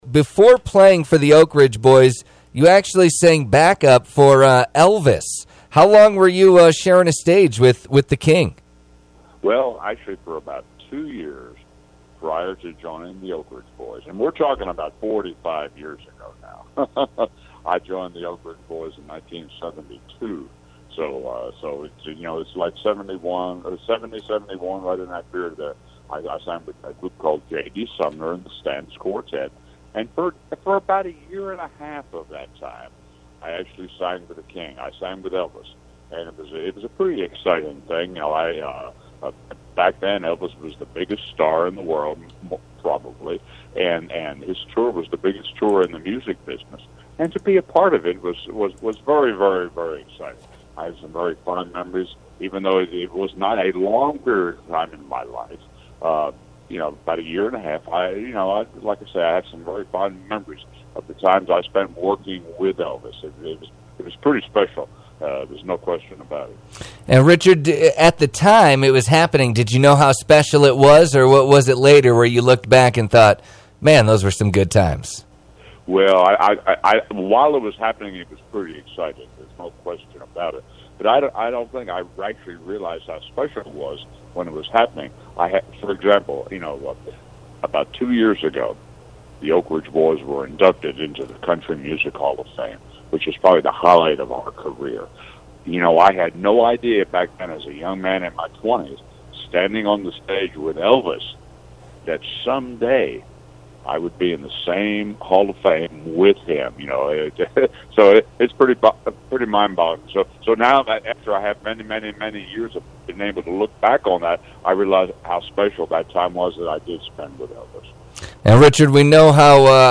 Richard Steerban of the Oak Ridge Boys discusses his upcoming show in Sheridan. We also discuss his time on the road with Elvis Presley, leaving Elvis’ crew to join the Oak Ridge Boys, being inducted into the Country Music Hall of Fame, and more. Listen to the full interview here: